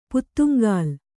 ♪ puttuŋgāl